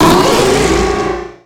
Cri de Méga-Pharamp dans Pokémon X et Y.
Cri_0181_Méga_XY.ogg